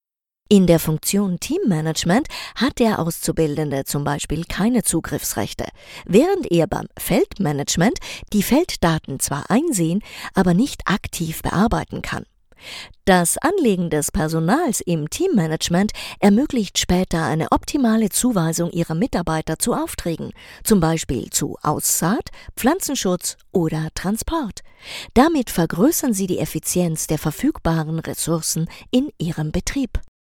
sehr vielseitige, erfahrene Theater und Filmschauspielerin deutsch österreichisches deutsch
wienerisch
Sprechprobe: eLearning (Muttersprache):
very experienced actress and voice actress stage-tv-movie-microfone